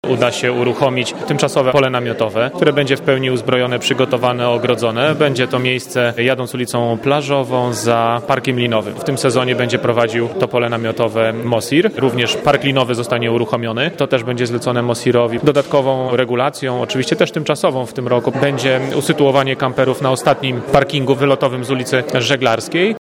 Wymienia prezydent Tarnobrzega Łukasz Nowak.